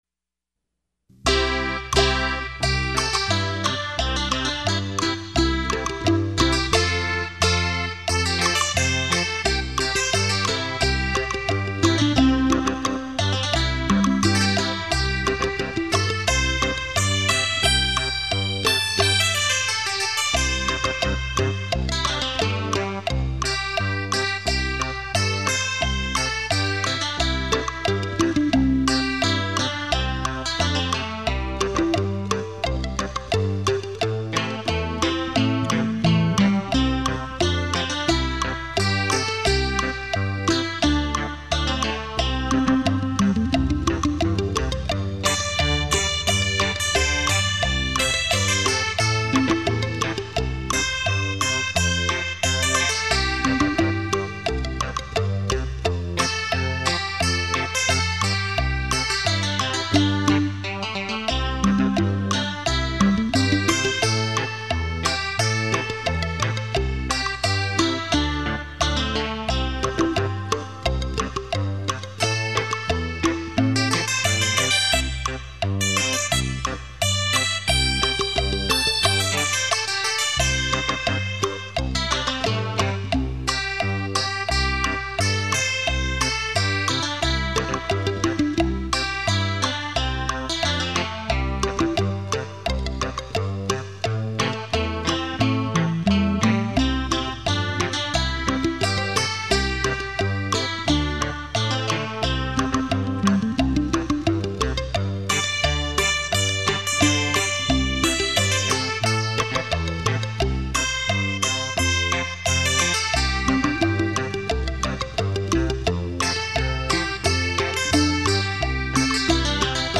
立体音声 环绕效果
100%绝佳的听觉享受度 100%声历身超级震撼度 100%立体声雷射音效